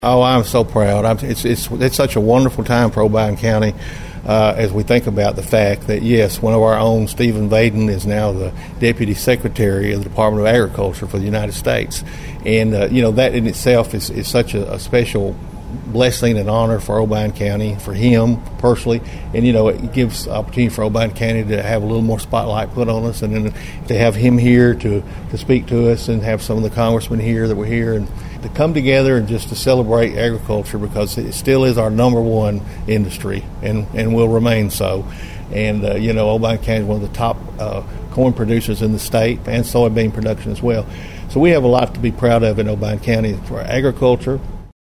Mayor Carr talked about Vaden following the recent Farmers Harvest Breakfast held at the fairgrounds.(AUDIO)